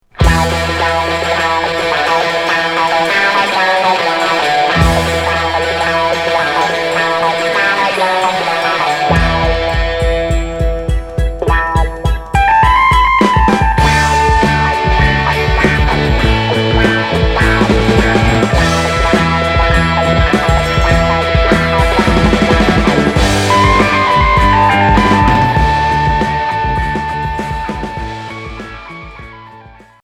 Rock psychédélique